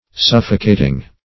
Suffocating \Suf"fo*ca`ting\,